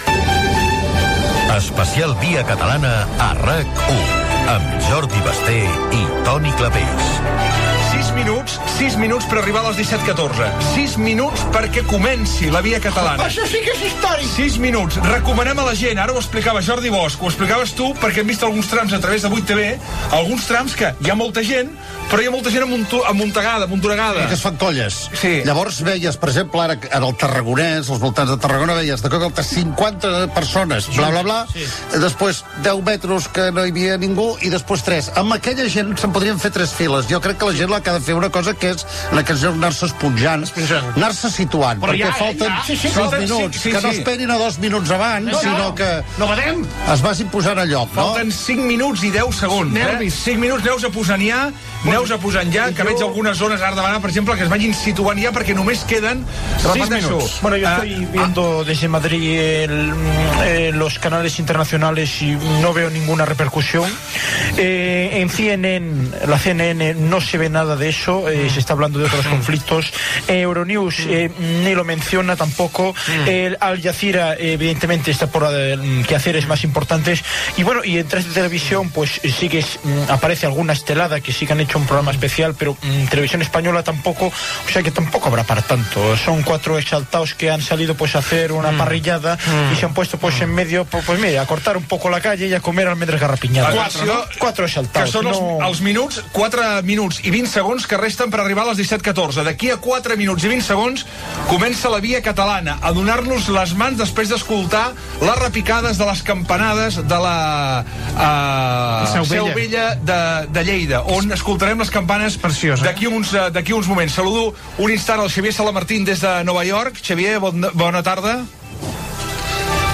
Èxit de la convocatòria, a les 17:14 campanades, des de Lleida, que marquen el moment d'enllaçar les mans, himne dels Segadors. Informació de la manifestació amb connexions amb la Plaça de Catalunya i la Sagrada Família de Barcelona, El Pertús, Vinaroç, El Vendrell, Esplugues de Llobregat, Amposta i La Canonja. Parlament del filòsof Xavier Rubert de Ventós, des de El Pertús, Entrevista al cantant Lluís Llach.
Gènere radiofònic Info-entreteniment